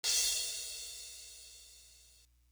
Crashes & Cymbals
Movie Crash.wav